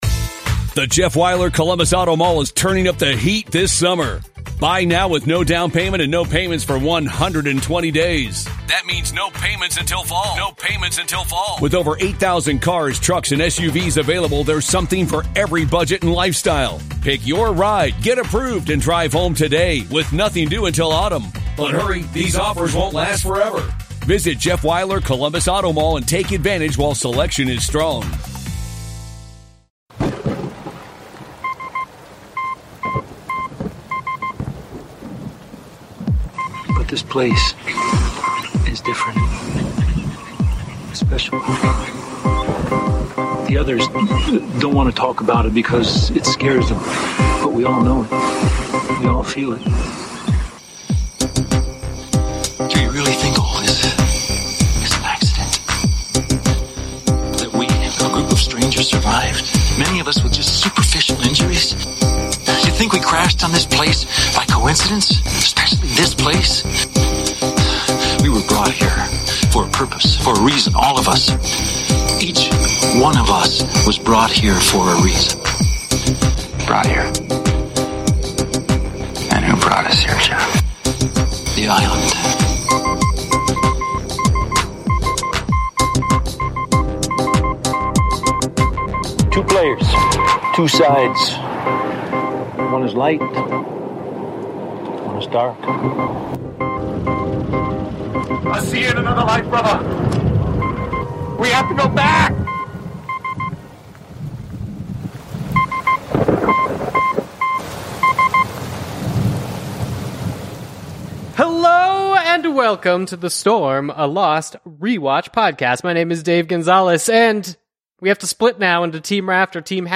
This week's podcast interview is with Alan Sepinwall, the chief TV critic of Rolling Stone and author of several books about television.